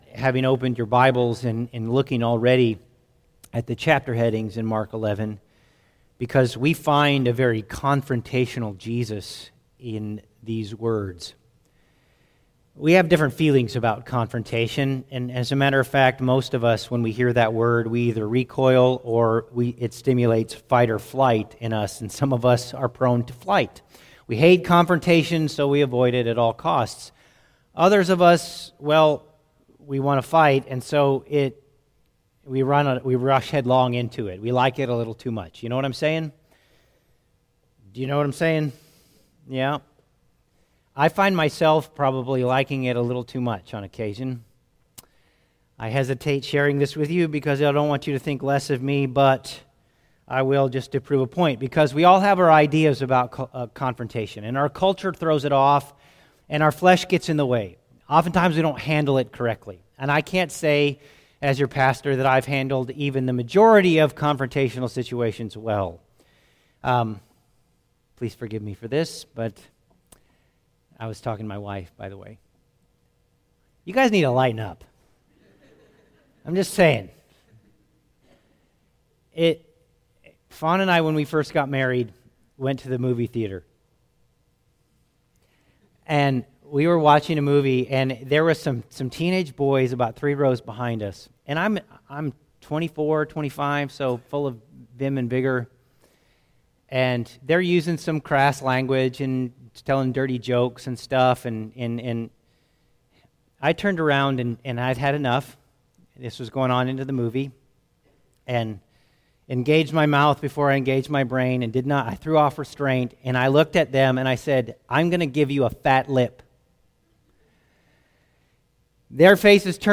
Phillips Chapel Free Will Baptist Church Sunday Morning Sermon